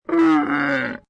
Camel 12 Sound Effect Free Download